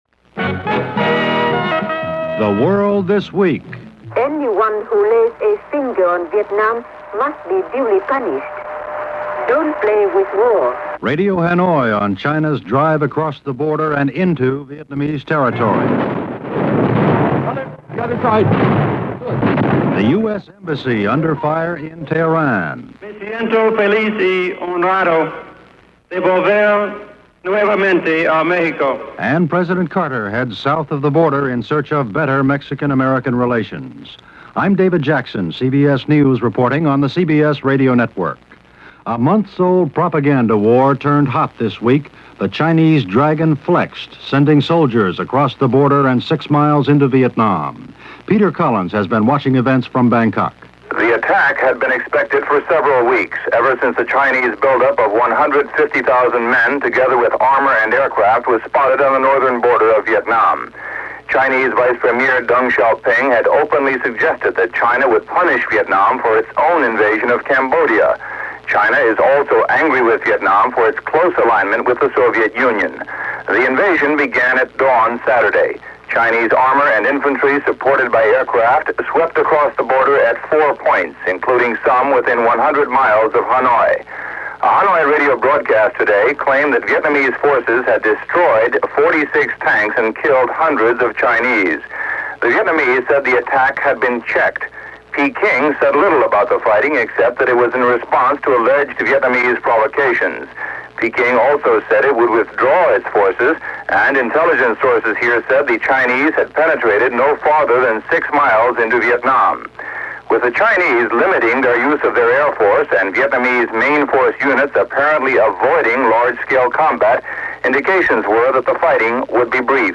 And while Tehran remained unstable, that’s how the week rolled, the one that ended this February 18th in 1979 as presented by CBS Radio’s The World This Week.